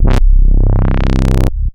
Roland A C1.wav